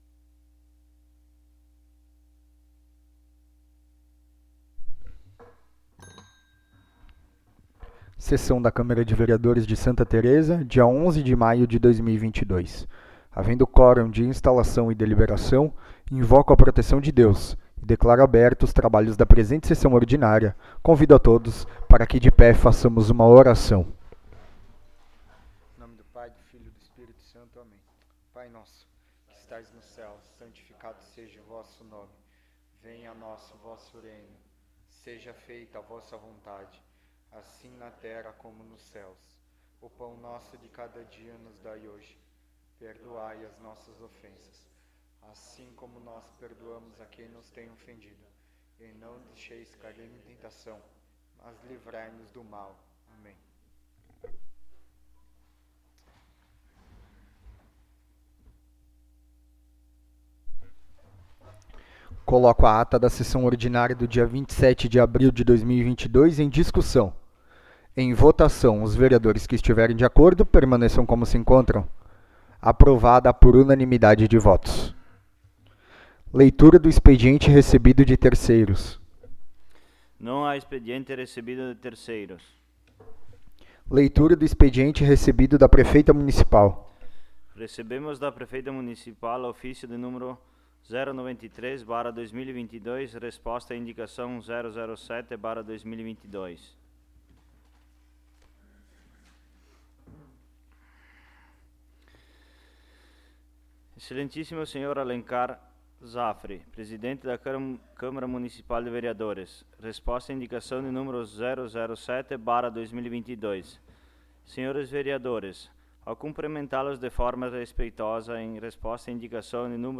7ª Sessão Ordinária de 2022